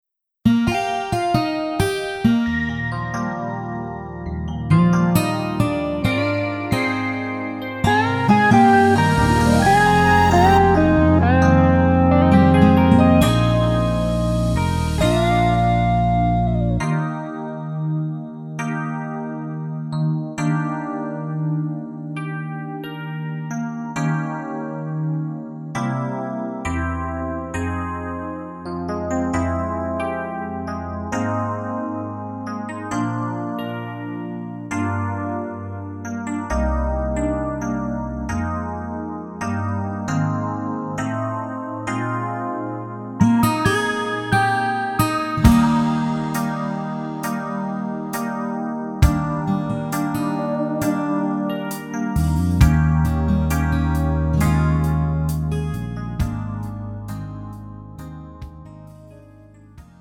음정 원키 3:37
장르 가요 구분 Pro MR